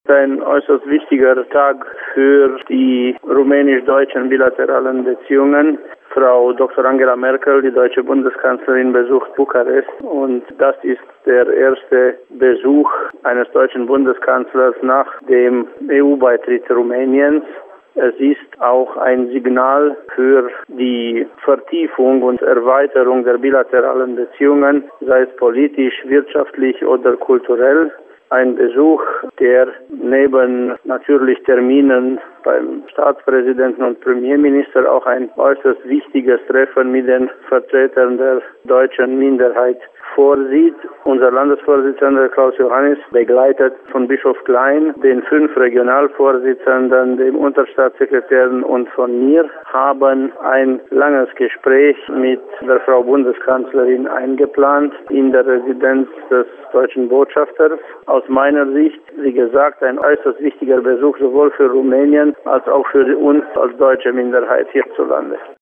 Zum morgigen Rum�nienbesuch der deutschen Bundeskanzlerin Angela Merkel erkl�rte per Telefon der Redaktion von Radio Temeswar gegen�ber der Abgeordnete der deutschen Minderheit im rum�nischen Parlament, Ovidiu Gant: